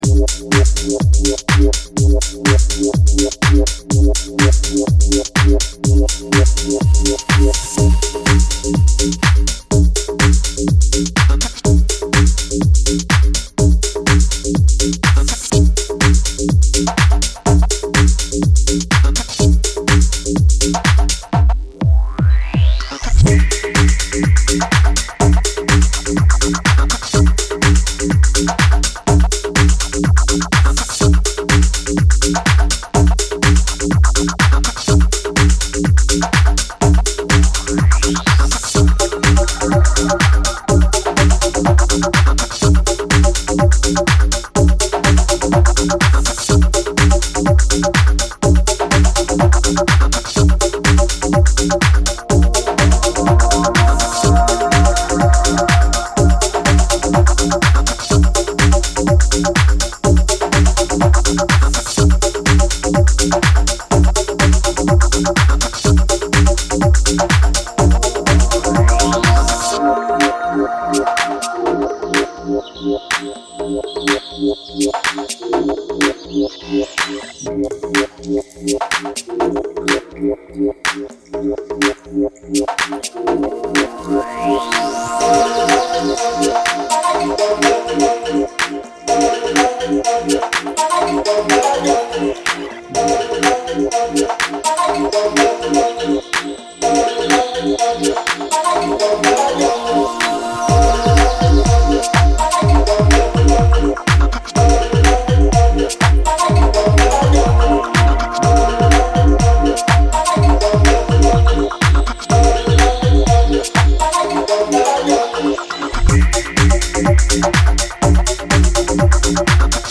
house, bass and rhythms